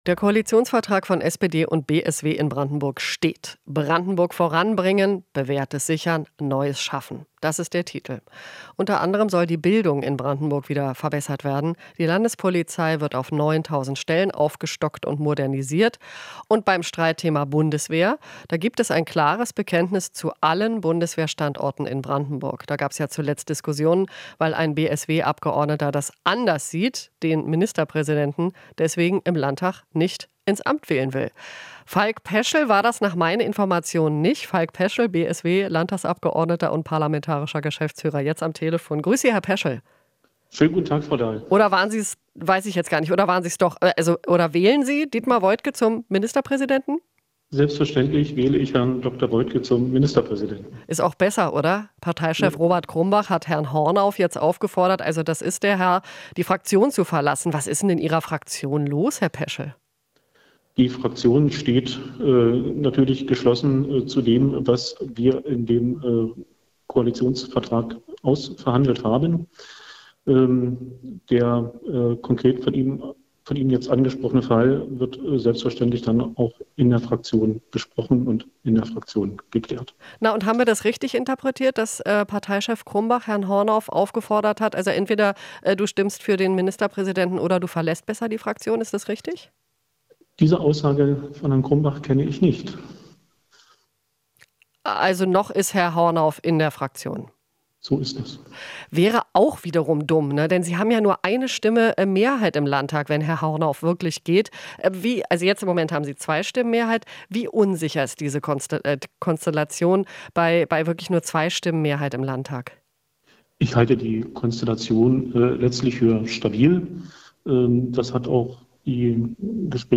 Interview - Peschel (BSW): Koalitionsvertrag für beide Seiten "sehr vertretbar"